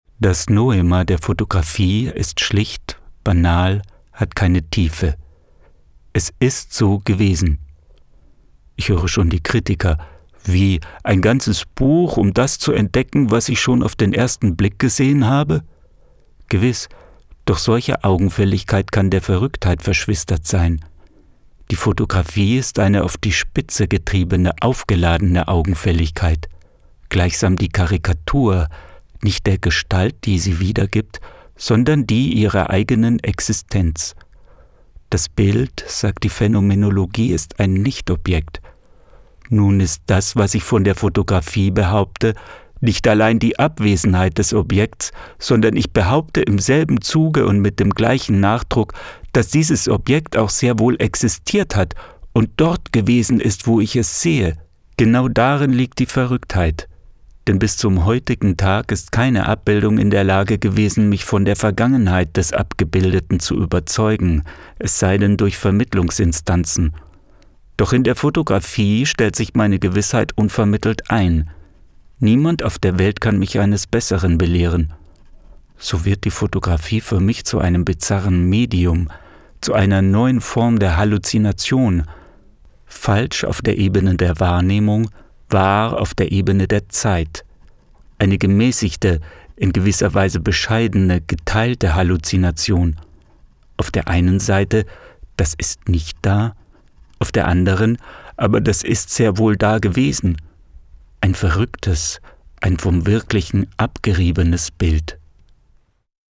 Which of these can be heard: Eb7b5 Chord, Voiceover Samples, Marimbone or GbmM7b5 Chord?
Voiceover Samples